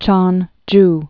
(chônj, chœn-)